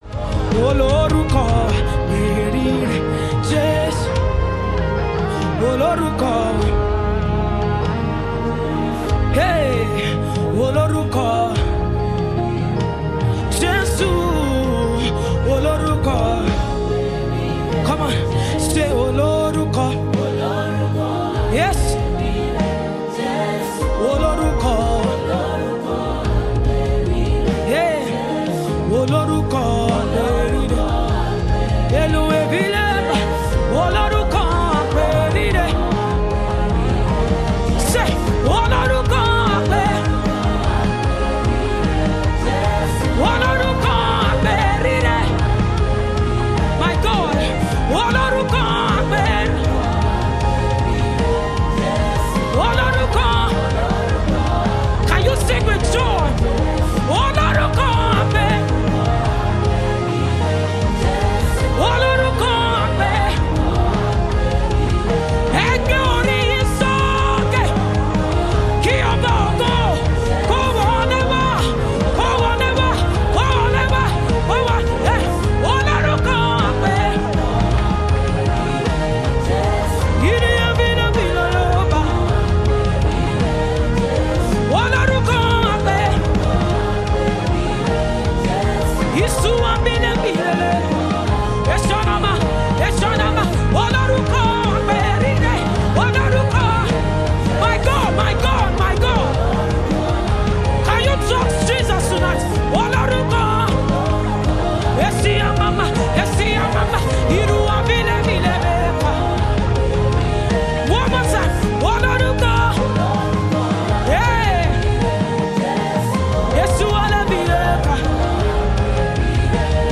Yoruba Gospel Music
sounds of praise and worship